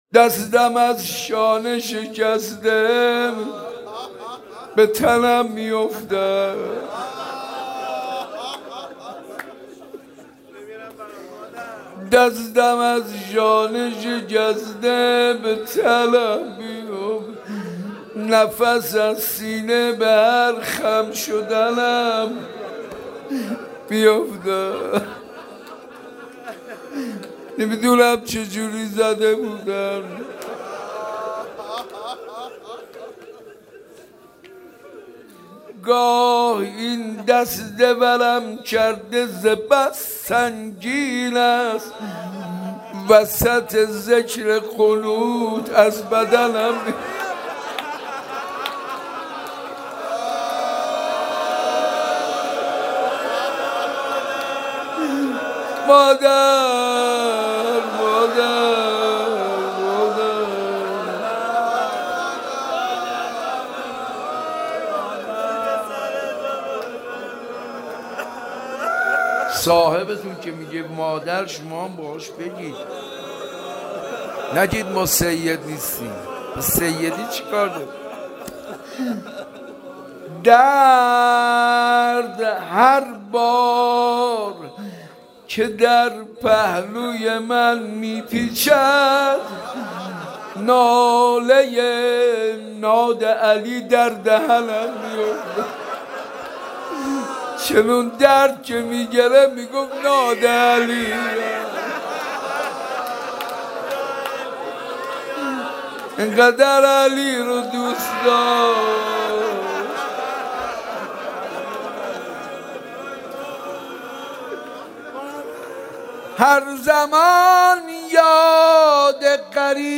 مداحی و نوحه
وسط ذکر قنوت از بدنم می اُفتد» [فاطمیه دوم] [روضه]